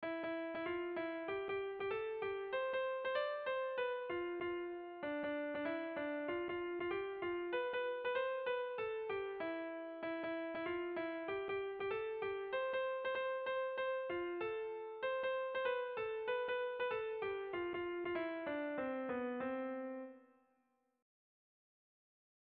Bertso melodies - View details   To know more about this section
Zortziko handia (hg) / Lau puntuko handia (ip)
ABAD